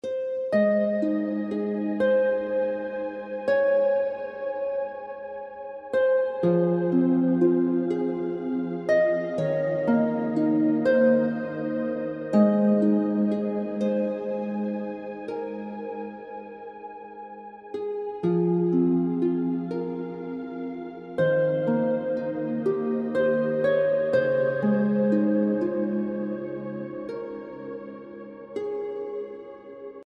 Heavenly Harp Music
harp